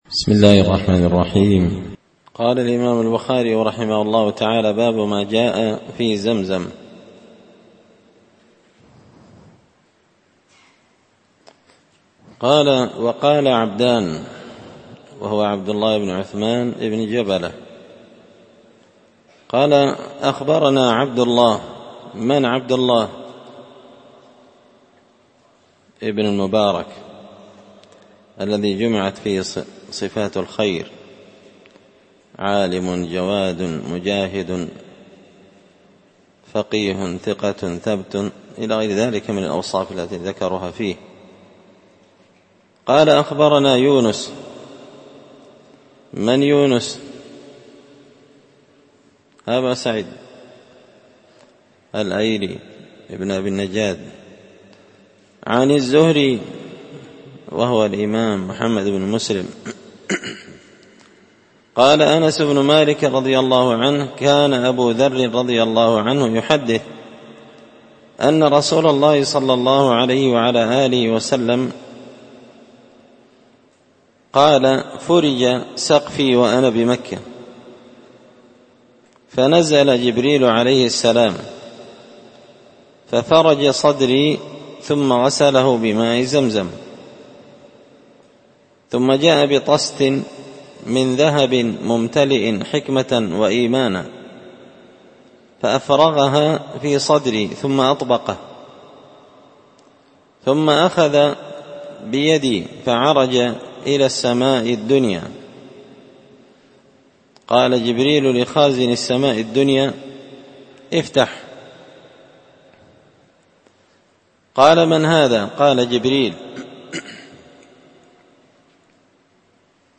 كتاب الحج من شرح صحيح البخاري – الدرس 66
دار الحديث بمسجد الفرقان ـ قشن ـ المهرة ـ اليمن